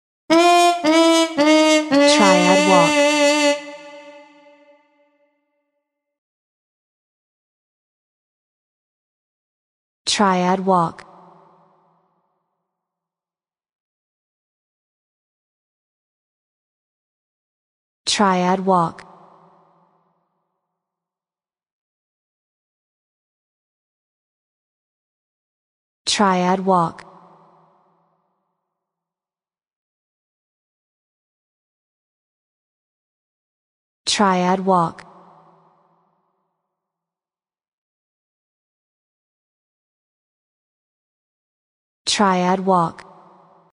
dSound effects for game over, trouble, etc.
Sound Logo , Trumpet